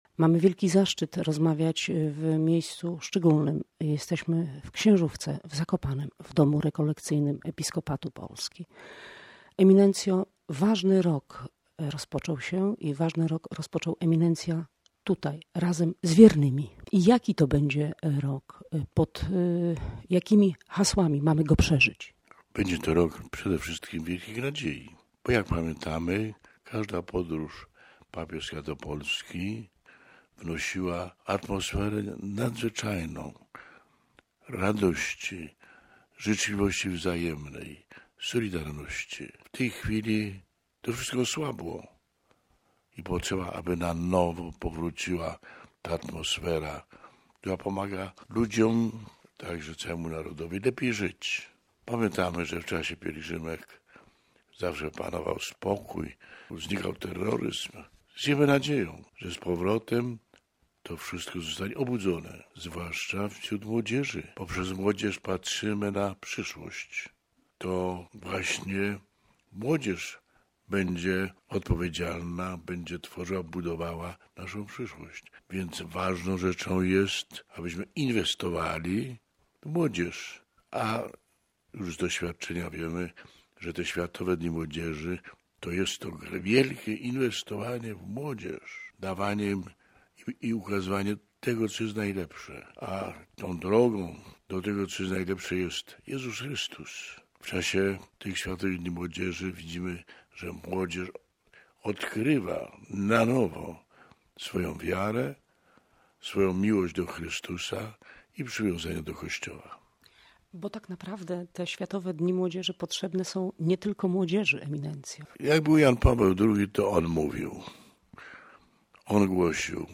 Posłuchaj wywiadu:/audio/dok1/dziwisz_stanislaw.mp3